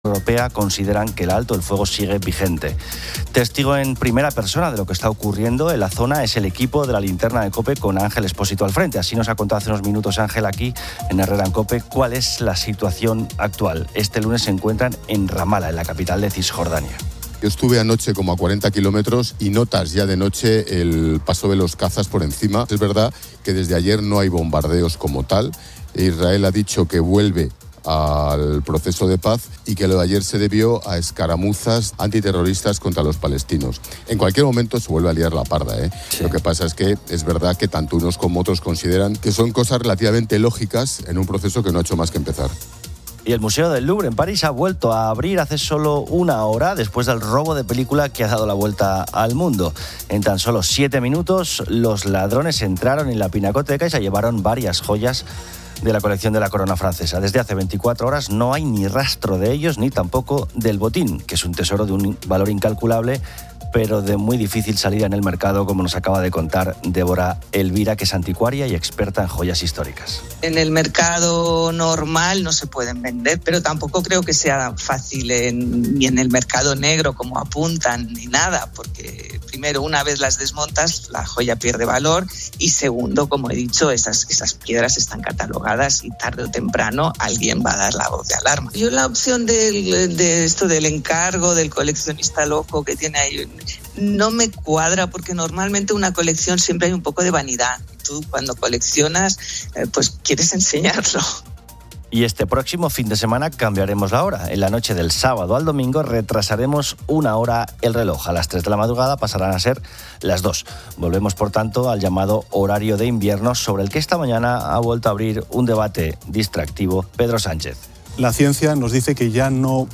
Carlos Herrera celebra su boda y dedica el programa a las historias de amor, pidiendo a los oyentes que compartan la mayor locura que han hecho por amor. Se escuchan relatos como tirarse de un tercer piso, casarse tres veces con la misma persona o que el marido haga el desayuno a diario.